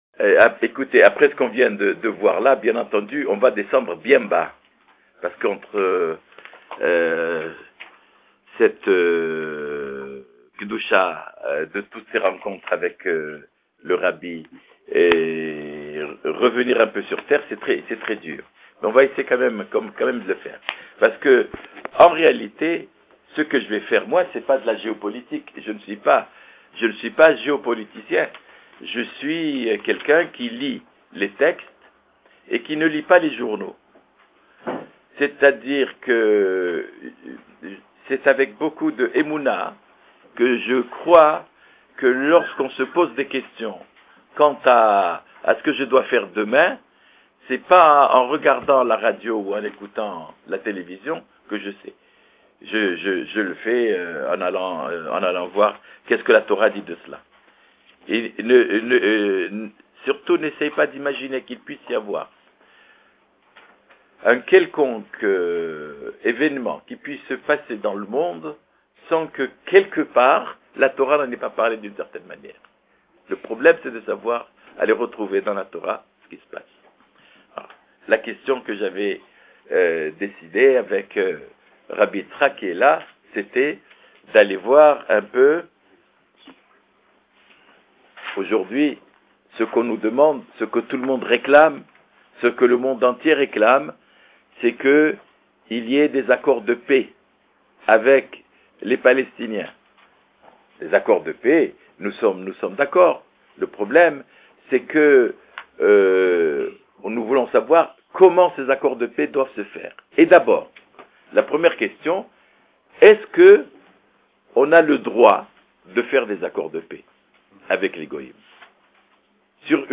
Cours dispensés au Beth 'Habad - en MP3